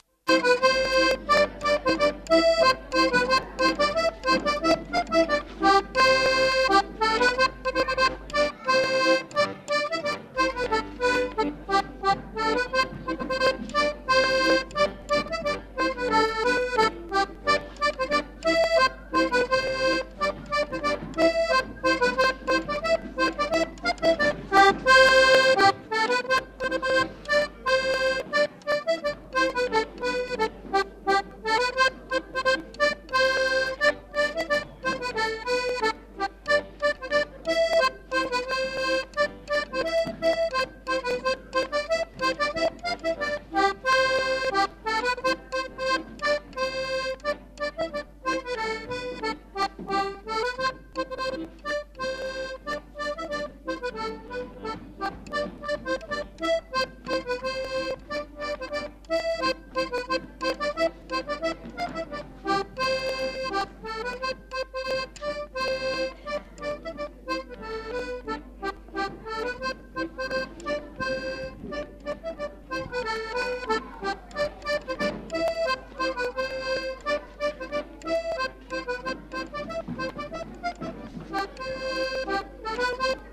Scottish Centre culturel.